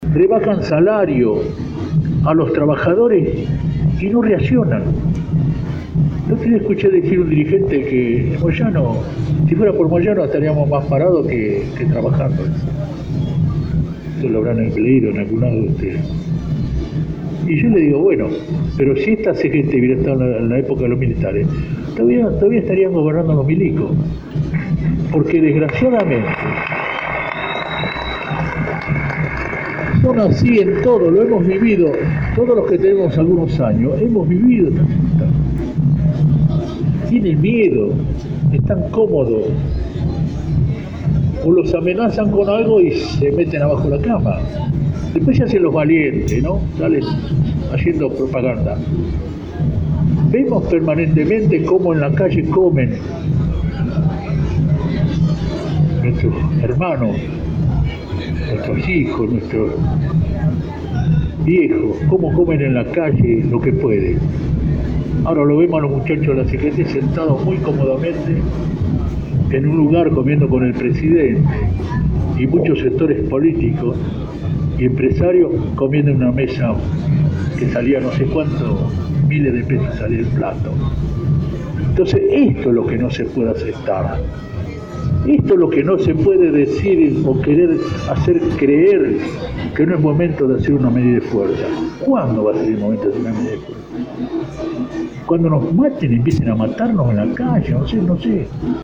Hugo Moyano, al tomar la palabra en el plenario de Smata, pintó un panorama oscuro sobre la realidad argentina y le propinó algunos dardos a la CGT por no convocar un paro.
El secretario general del gremio de Camioneros, Hugo Moyano, fue último orador del plenario de secretarios generales y de las regionales de la CGT en el sindicato de mecánicos SMATA, en donde se definió un paro general el 30 de abril.